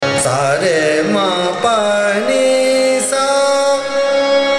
Raga
ThaatKhammaj
ArohaS R m P N S’